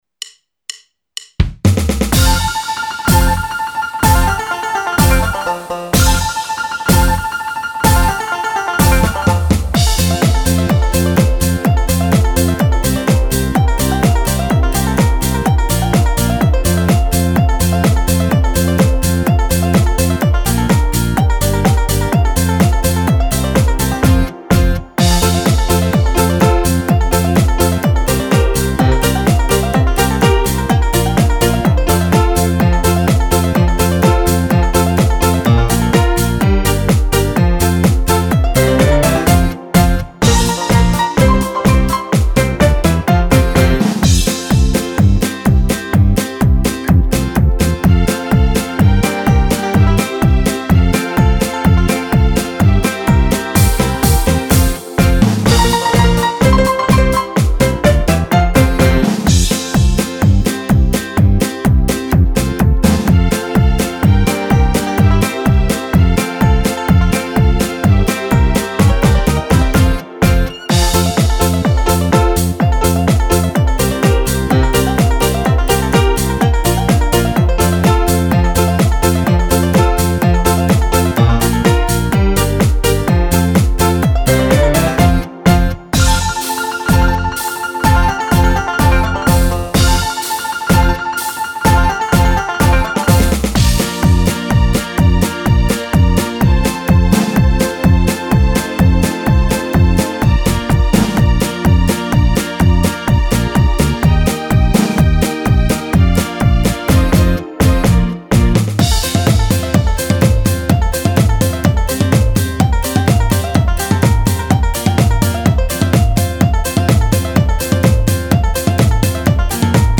Polka country per Fisarmonica e Violino.
Polka country
Base MP3